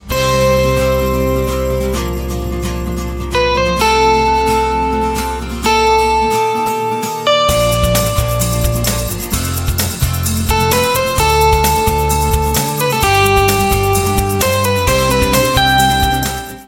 C  Bb  F  Eb  Bb  F  Dm  G  C
First, you’ll notice that though it starts on C, it moves quickly away from the typical chords you’d expect in that key, and it takes you on a bit of a harmonic journey into Bb major. But by the time you hear that Dm chord near the end, it’s all pulling back into the first key of C major.
Whatever the mechanics, it’s a nice dissonance.
Fragile-Strong_Demo.mp3